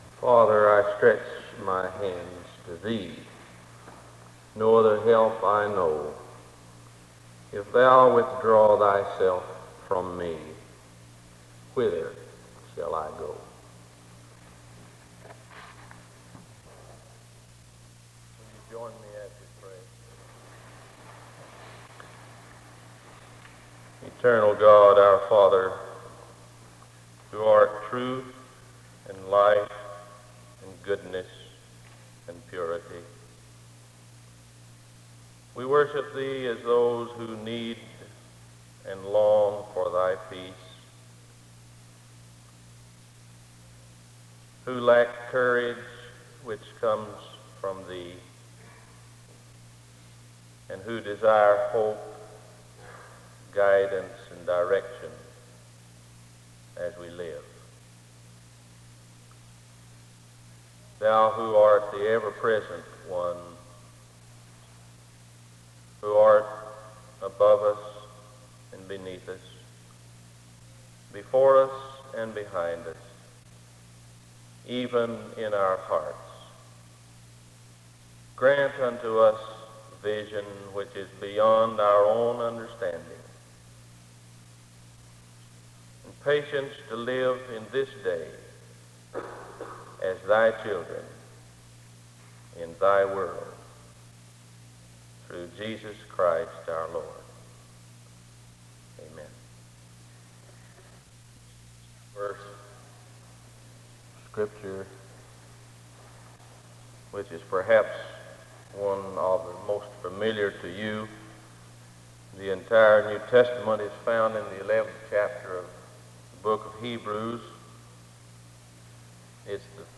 There is an opening prayer from 0:00-1:41.
SEBTS Chapel and Special Event Recordings SEBTS Chapel and Special Event Recordings